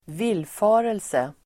Uttal: [²v'il:fa:relse]